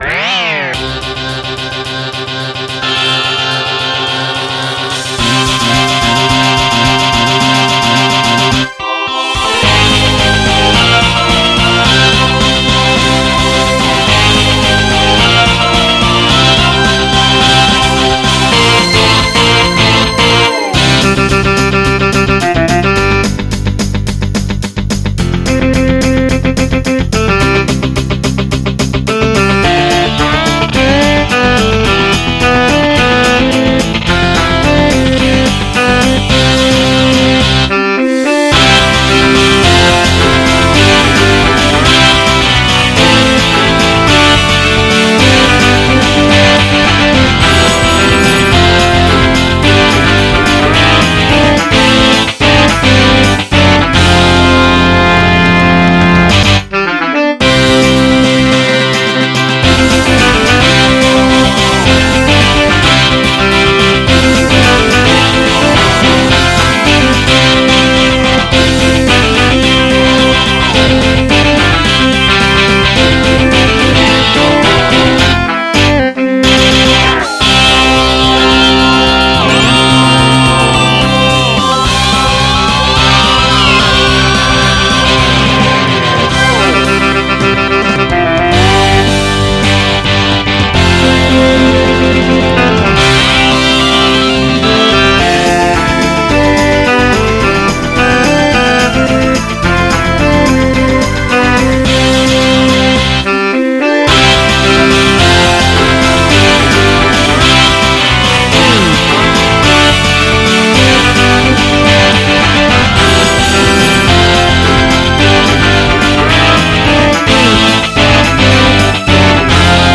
Note: track 1 will almost always Saxophone no matter what.